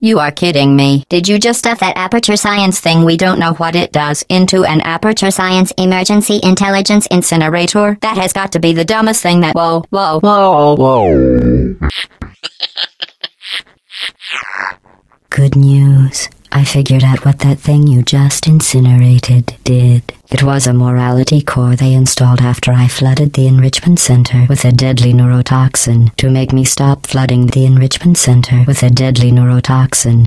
A portion of GLaDOS' speech that comes near the end of the game as she shifts from her robotic voice into her more sadistic and human-sounding voice.
Sound sample from Portal files; copyright Valve Corporation
GLaDOS_Voice_Change.ogg.mp3